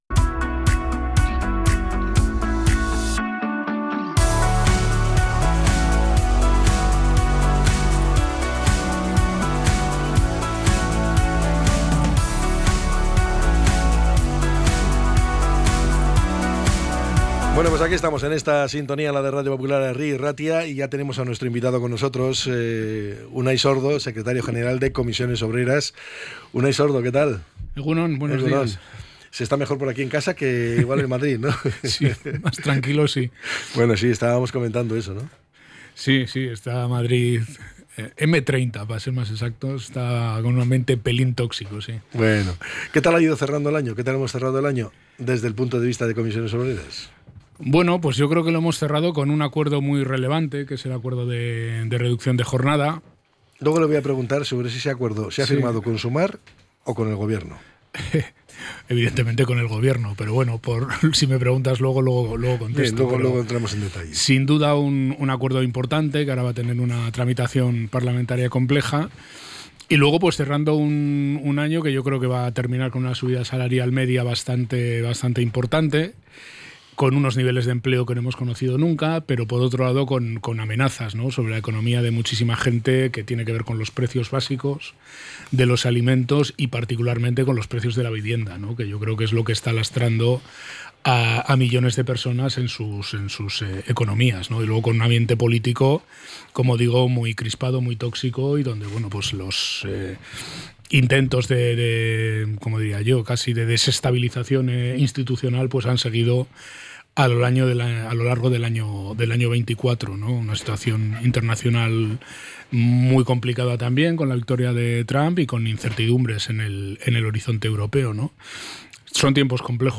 Entrevista con Unai Sordo, Secretario General de CCOO